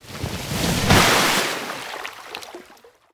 Sfx_creature_pinnacarid_dive_01.ogg